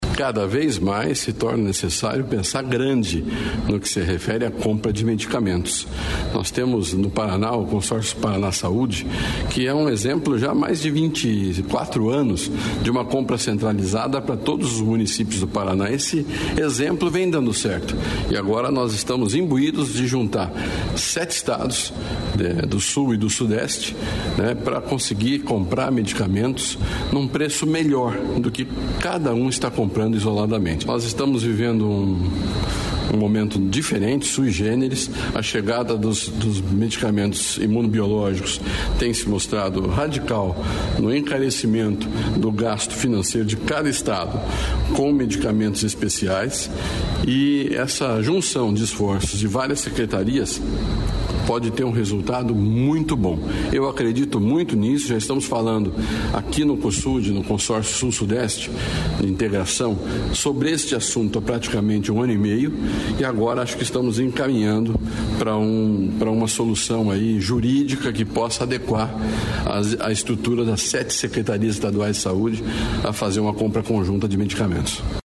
Sonora do secretário da Saúde, Beto Preto, sobre compra de medicamentos por consórcio